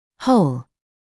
[həul][хоул]целый, весь; полный